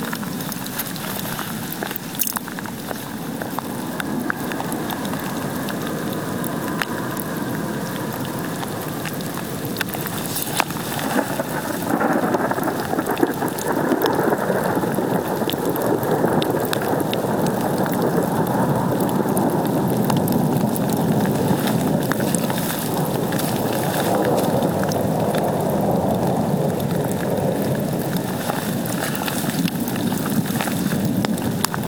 Thunder during a storm on the road in Indiana. Boy do I wish I had a better microphone.